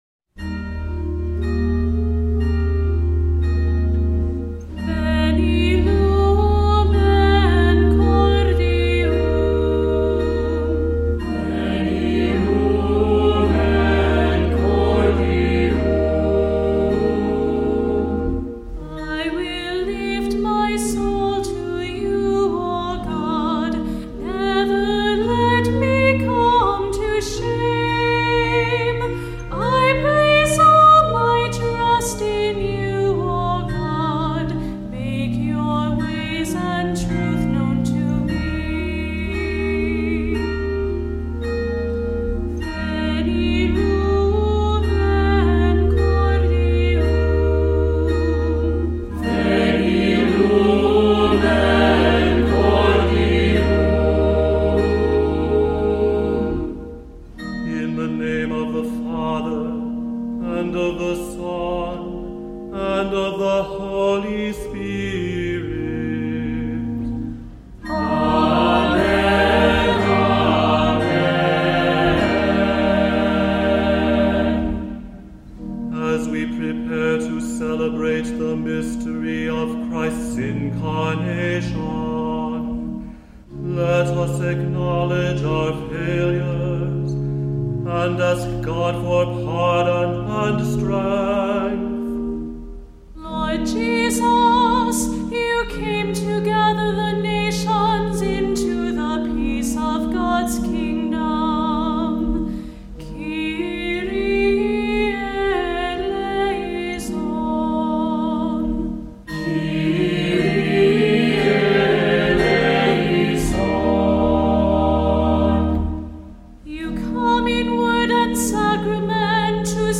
Voicing: SAB; Cantor; Presider; Assembly